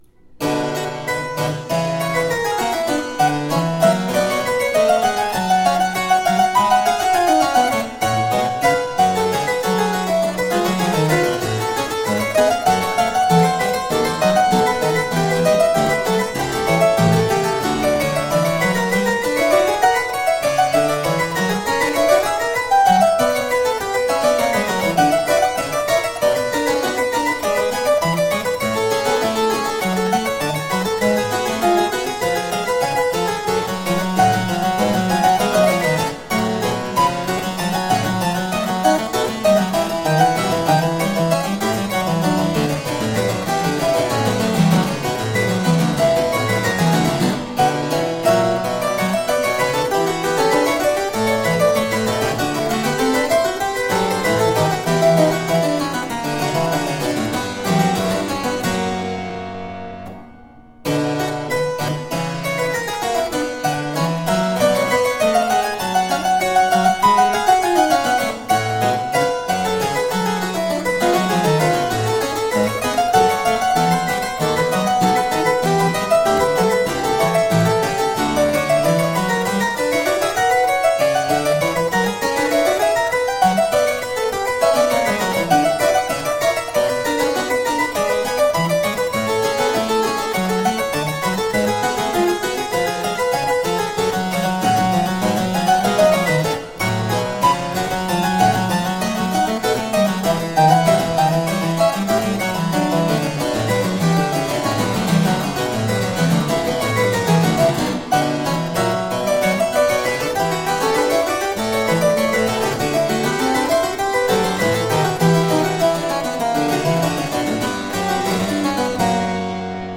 three different beautiful harpsichords
Classical, Baroque, Instrumental, Harpsichord